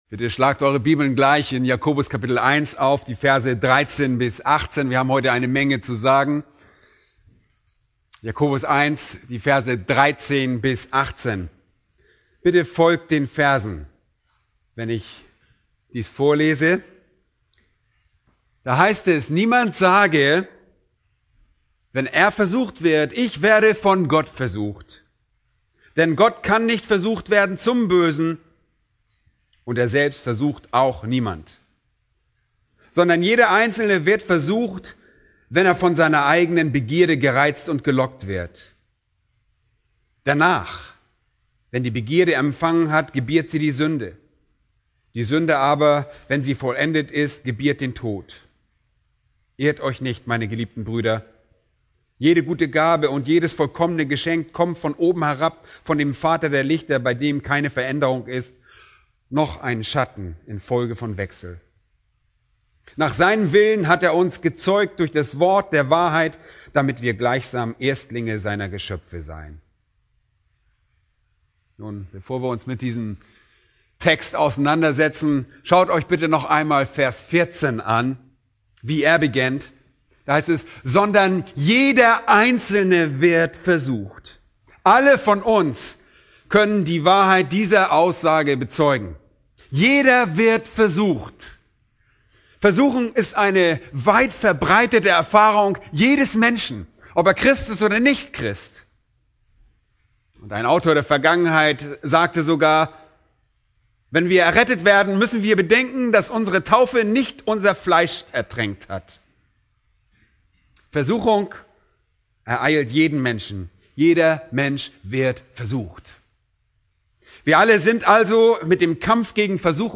Predigt: "1. Kor. 12,8-11"